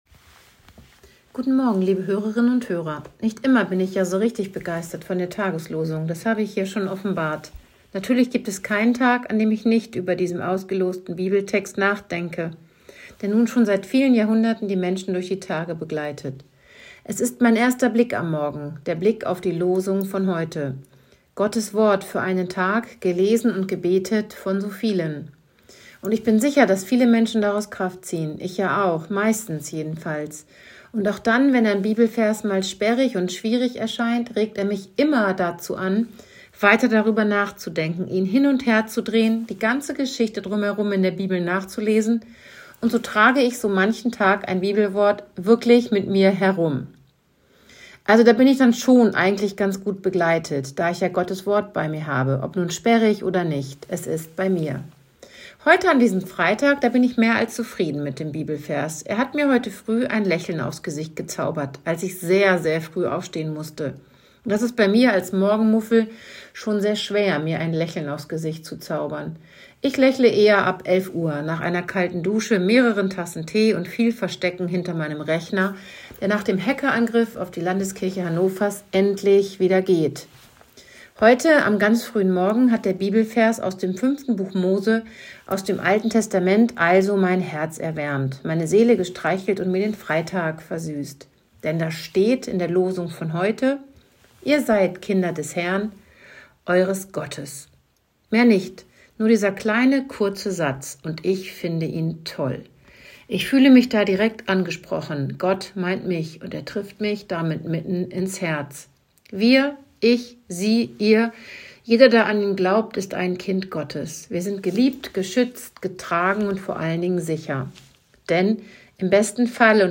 Radioandacht vom 15. März